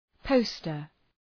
Προφορά
{‘pəʋstər}